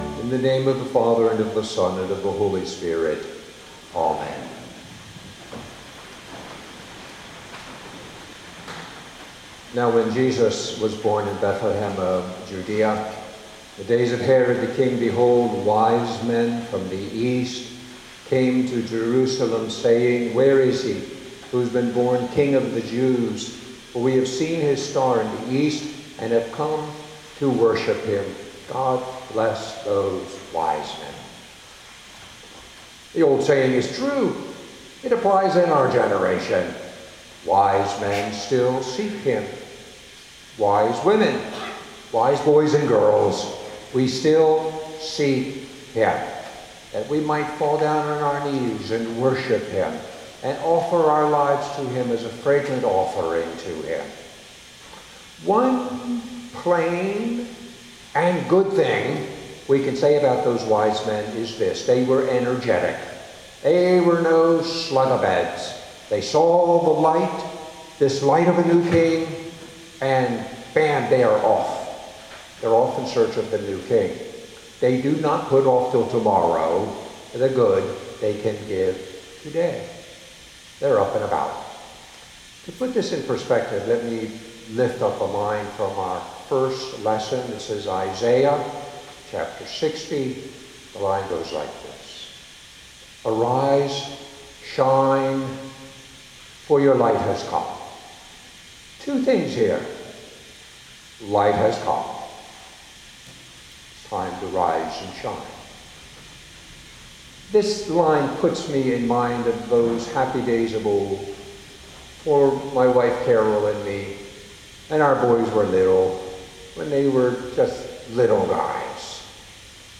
sermon.mp3